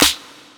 Number One Snare Clap.wav